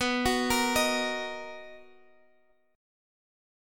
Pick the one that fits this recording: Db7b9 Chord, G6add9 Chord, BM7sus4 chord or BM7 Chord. BM7 Chord